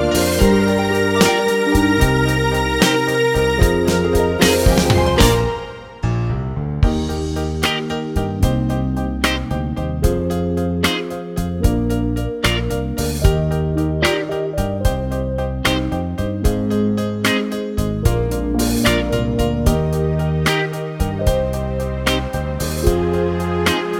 No Backing Vocals Soundtracks 3:55 Buy £1.50